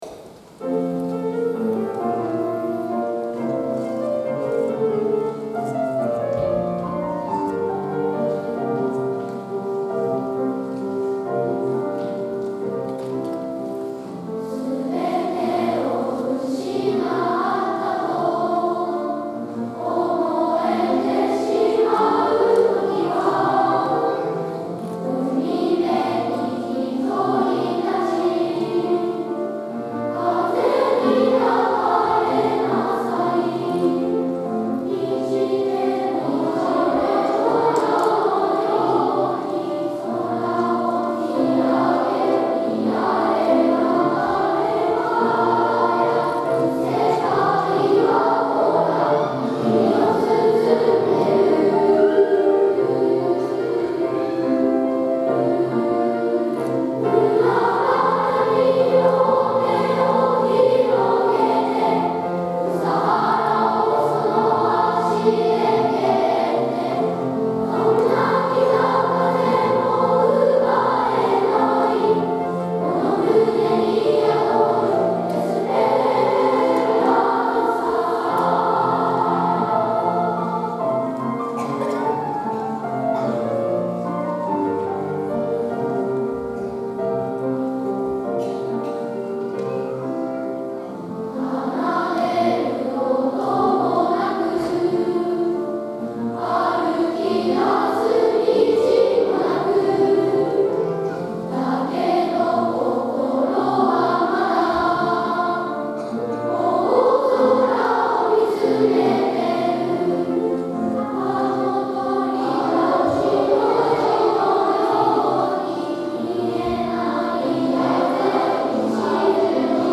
大空ありがとうコンサート
エスペランサ〜希望〜」４・５・６年合唱です。
一人ひとりが自分の心にある「希望」をイメージしながら、ありがとうの気持ちを歌詞に込めて、会場中に思いが届くように歌いました♪